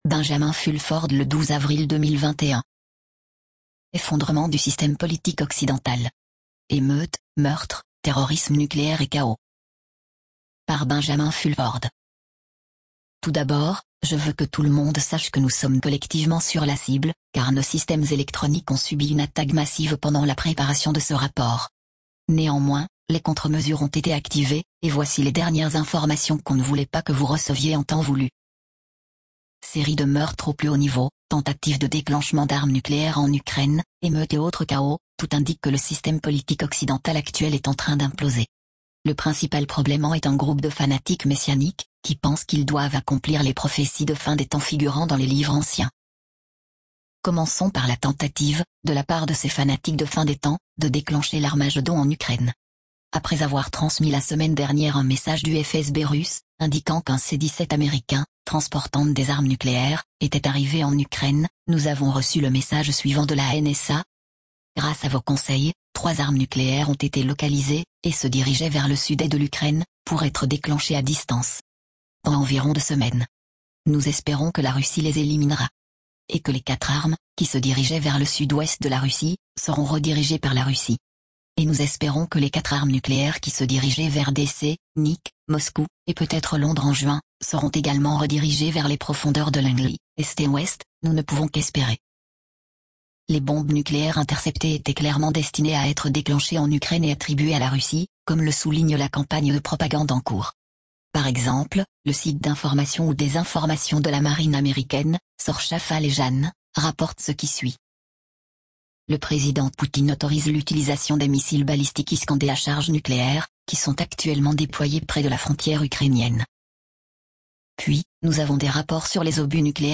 Traduction ====> L'avocat Reiner Fuellmich fait le point sur `` Nuremberg 2 '': il y a de la lumière au bout du tunnel